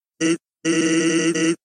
Undertale Sans Talking Sound Button: Unblocked Meme Soundboard
Play the iconic Undertale Sans Talking sound button for your meme soundboard!